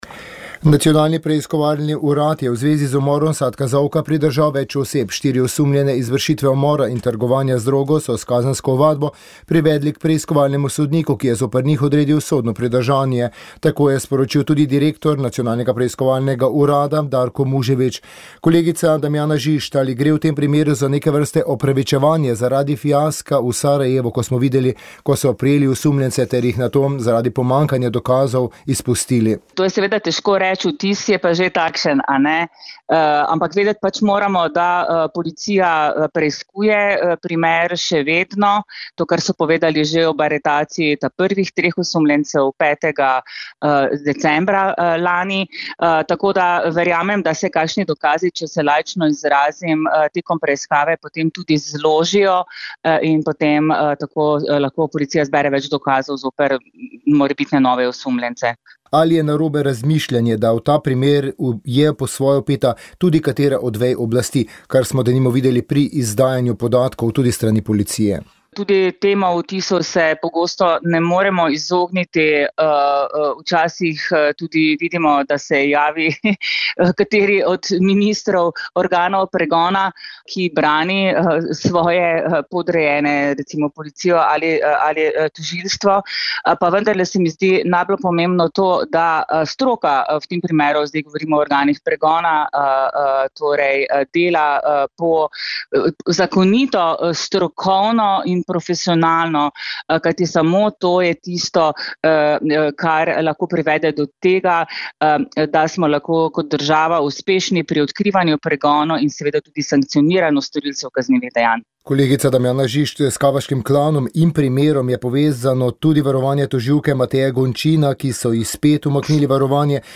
Okrajno sodišče je izreklo prvo obsodilno sodbo štirim policistom, ki naj bi bili obsojeni na večmesečno pogojno zaporno kazen. Poklicali smo nekdanjega ministra za notranje zadeve Aleša Hojsa, ki je si je protest takrat ogledal v živo.
pogovor